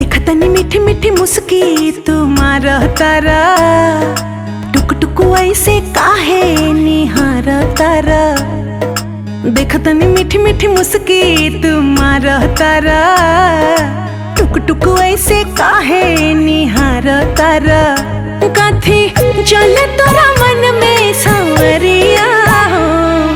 this song is a true Bhojpuri gem.